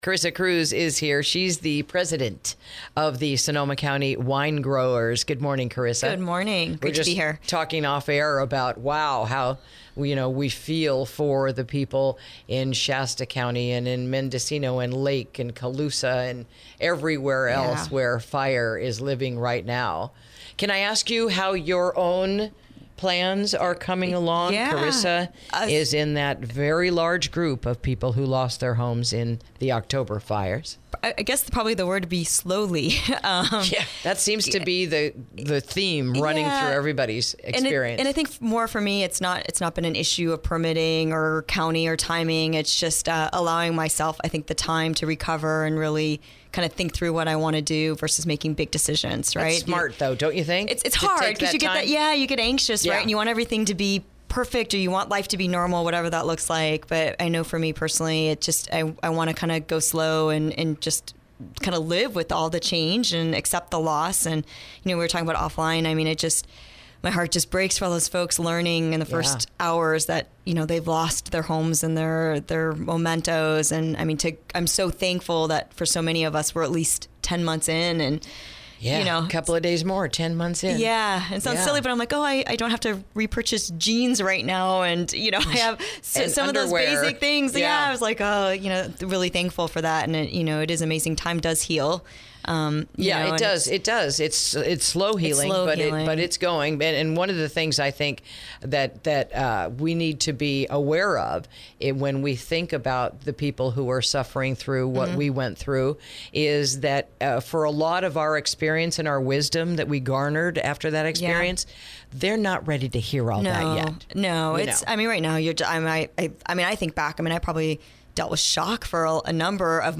INTERVIEW: The State of Affairs Surrounding This Year’s Wine Harvest Season